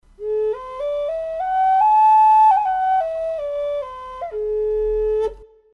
Пимак А Тональность: A
Проста в исполнении, но имеет вполне достойное звучание.